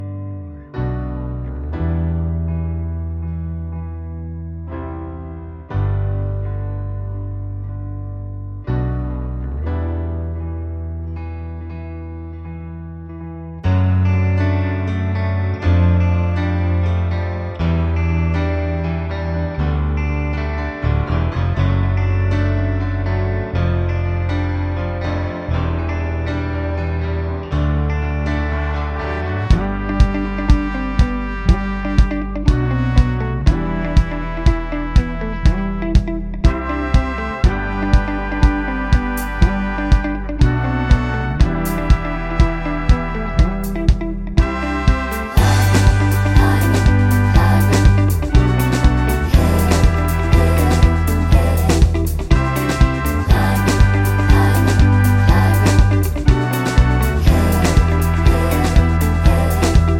no Backing Vocals Musicals 4:02 Buy £1.50